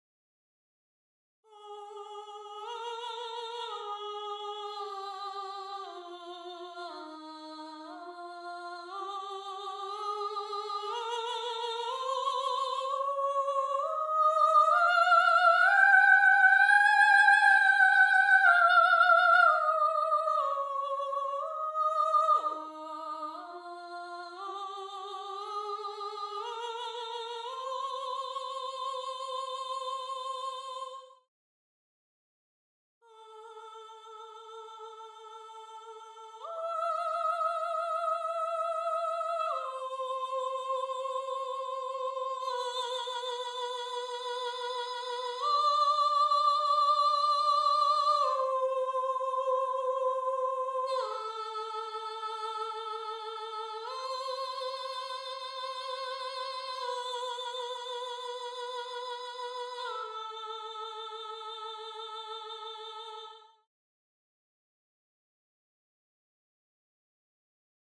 1. SOPRANO (Soprano/Soprano)